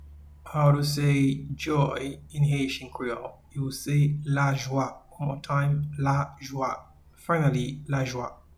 Pronunciation:
Joy-in-Haitian-Creole-Lajwa.mp3